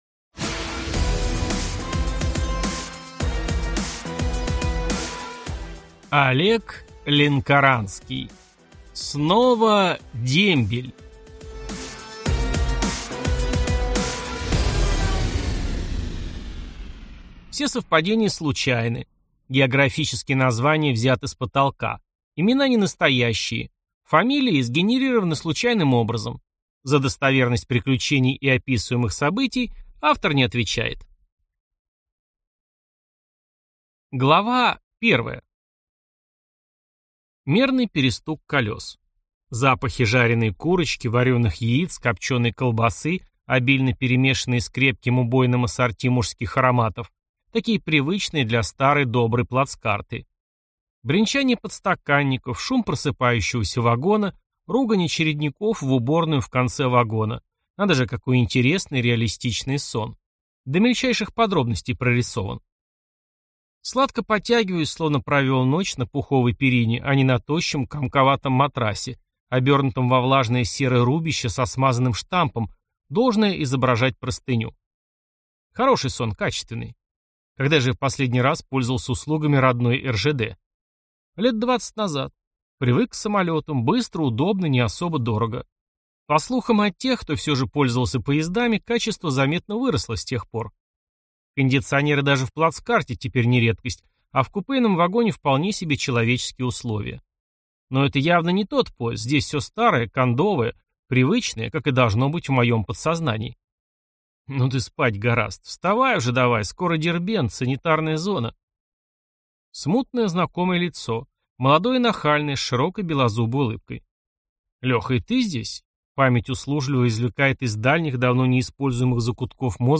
Аудиокнига Снова дембель | Библиотека аудиокниг
Прослушать и бесплатно скачать фрагмент аудиокниги